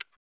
m1a1_new_empty.ogg